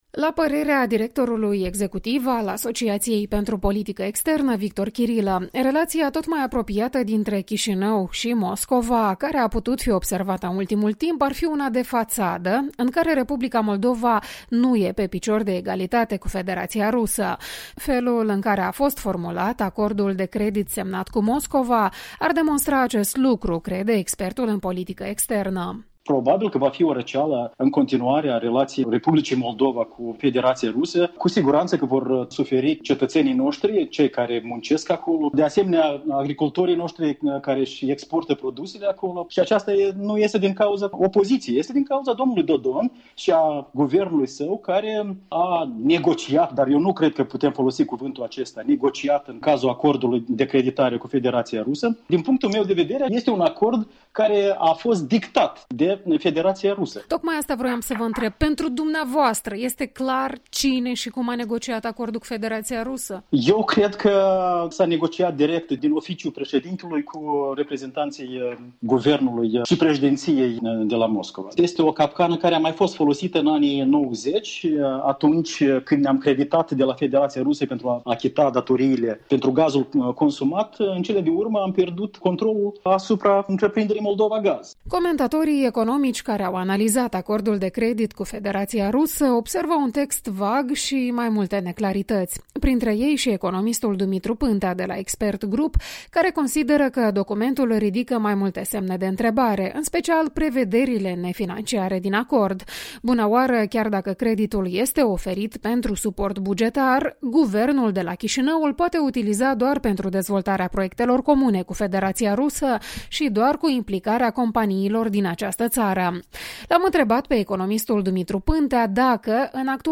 Retrospectiva săptămânii despre creditul rusesc.